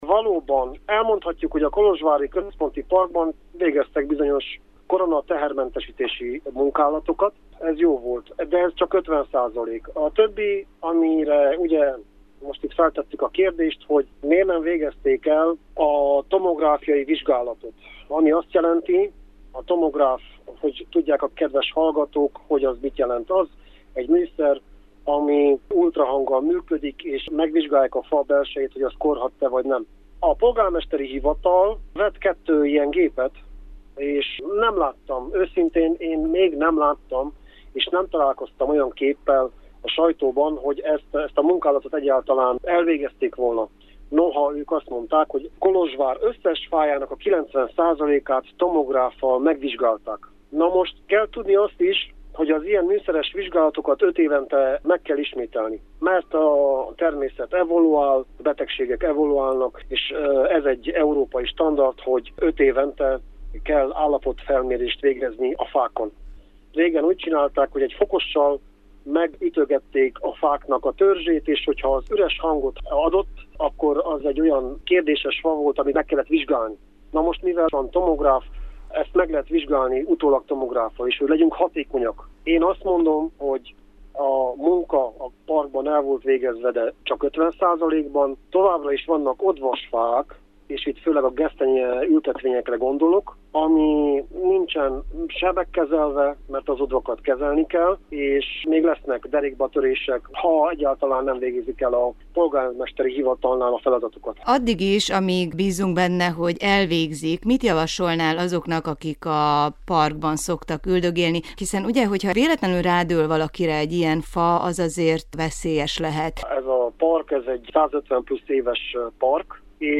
beszélgetett.